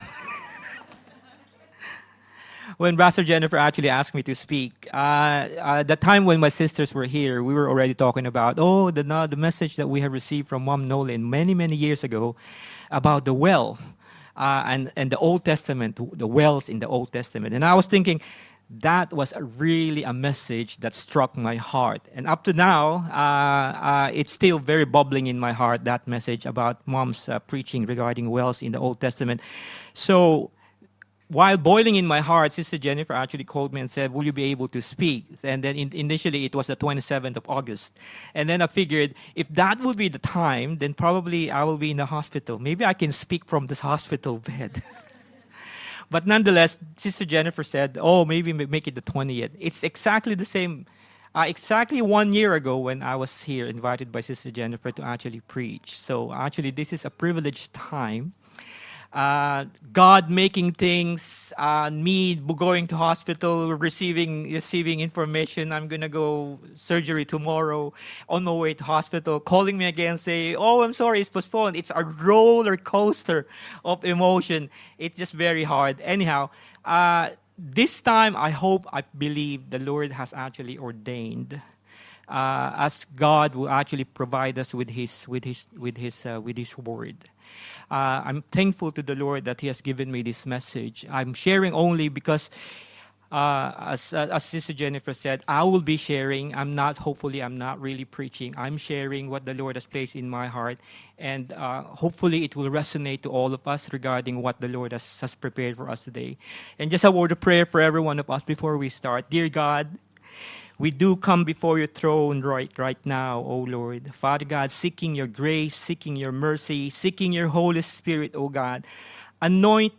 Notes Discussion Wells of Living Water Sermon By